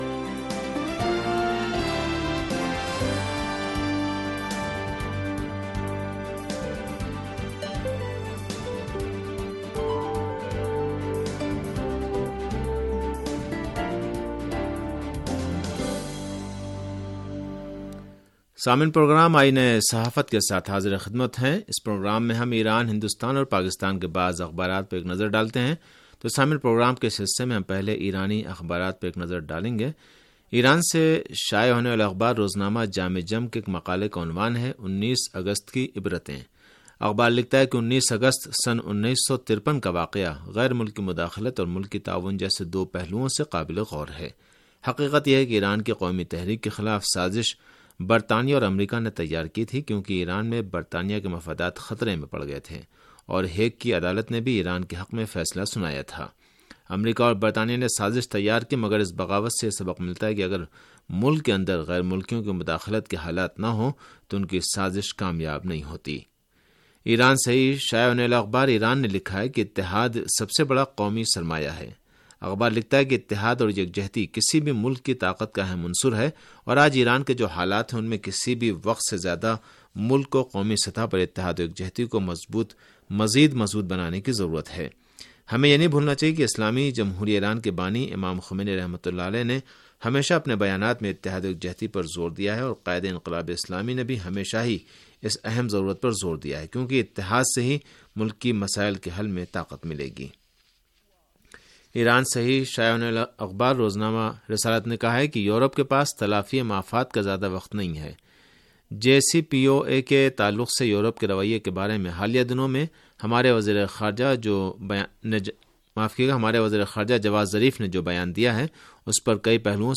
ریڈیو تہران کا اخبارات کے جائزے پر مبنی پروگرام